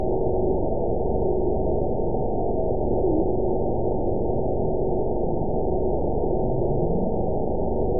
event 922285 date 12/29/24 time 03:25:44 GMT (5 months, 3 weeks ago) score 9.37 location TSS-AB02 detected by nrw target species NRW annotations +NRW Spectrogram: Frequency (kHz) vs. Time (s) audio not available .wav